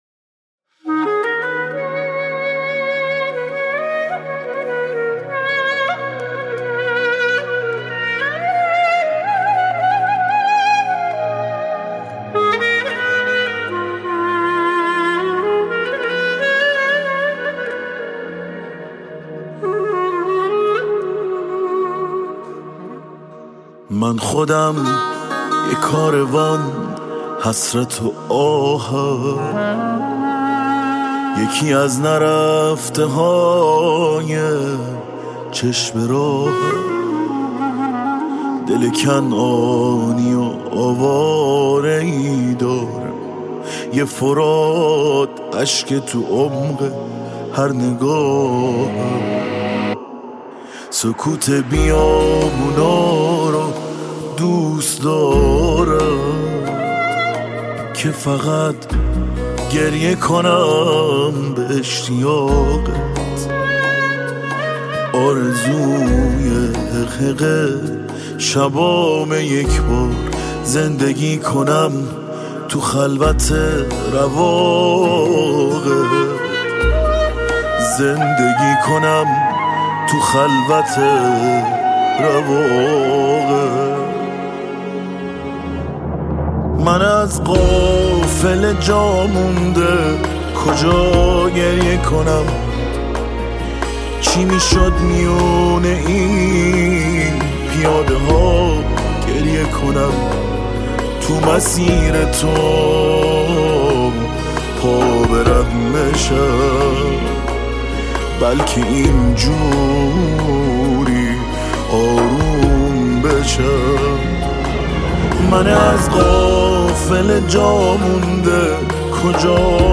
دانلود آهنگ غمگین
دانلود آهنگ های پاپ محرم